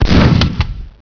guncock.wav